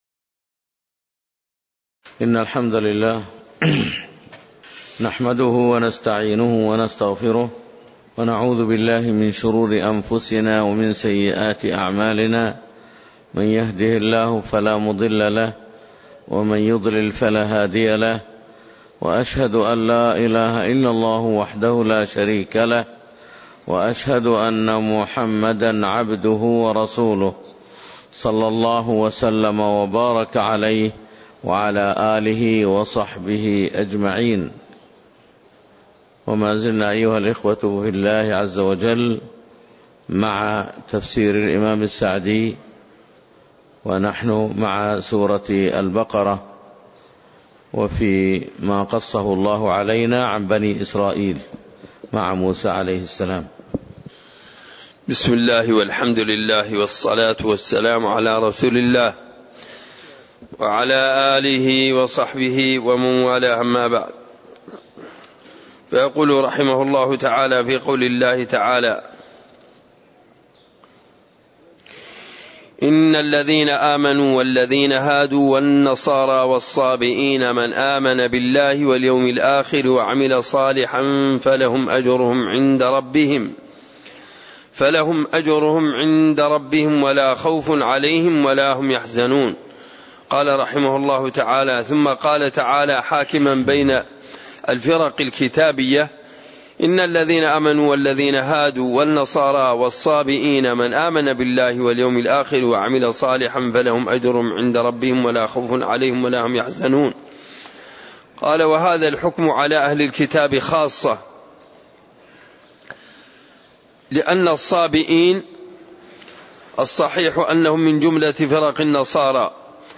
تاريخ النشر ٢٨ محرم ١٤٣٨ هـ المكان: المسجد النبوي الشيخ